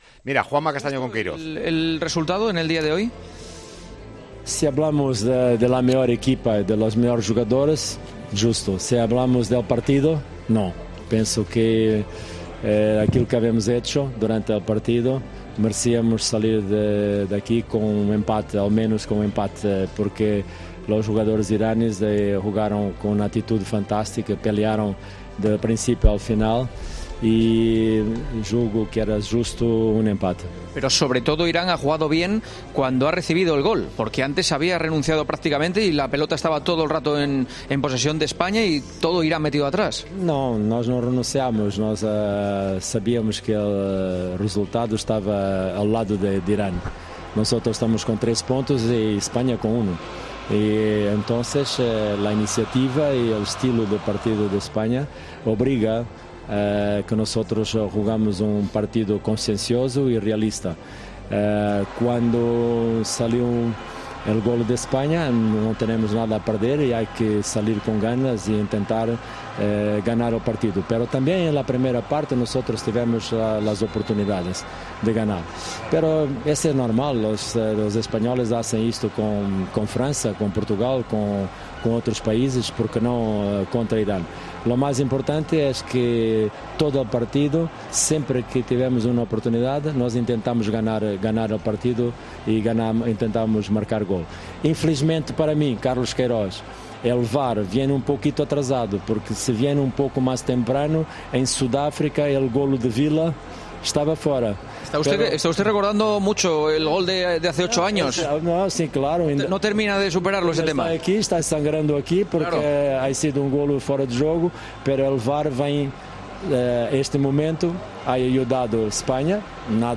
"El VAR para mí va un poco tarde y es una pena que no haya llegado en Sudáfrica para anular el gol de Villa ante Portugal. Está de lado de España. Cuando marca en fuera de juego no hay VAR y si lo hacemos nosotros sí lo hay", aseguró en rueda de prensa.